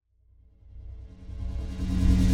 generator_start.ogg